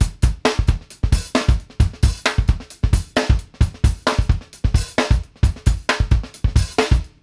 FOOT FUNK -L.wav